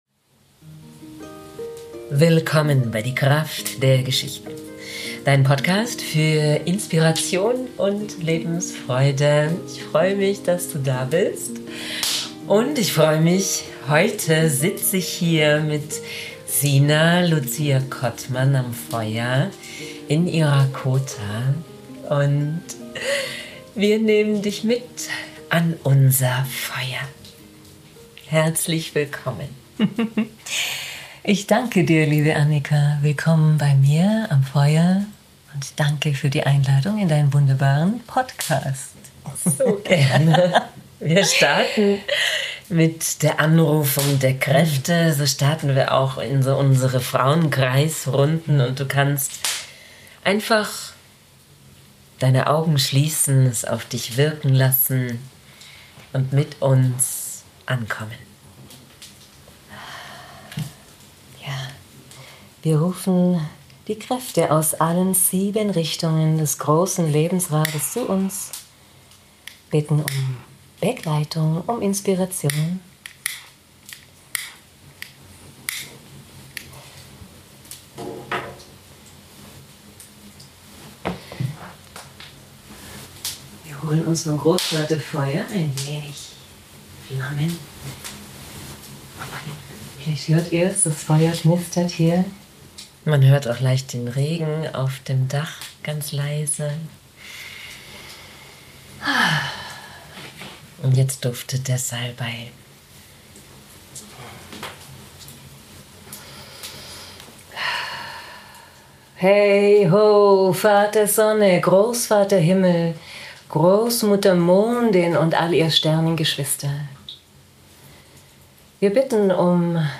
So viel Freude und Inspiration mit diesem kraftvollen Interview wünsche ich Dir.